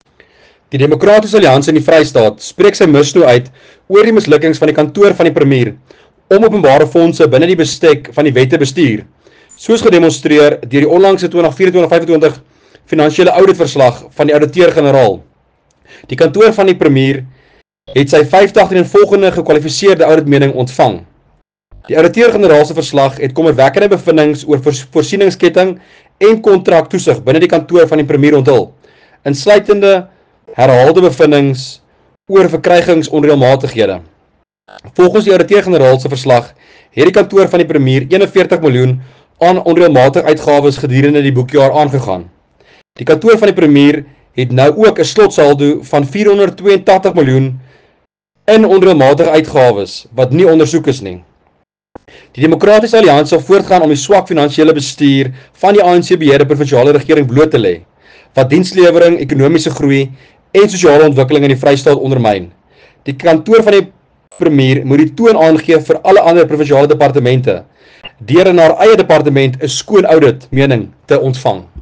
Afrikaans soundbites by Werner Pretorius MPL and Sesotho soundbite by Jafta Mokoena MPL